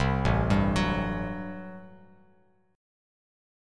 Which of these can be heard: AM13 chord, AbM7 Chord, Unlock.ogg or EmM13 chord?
AM13 chord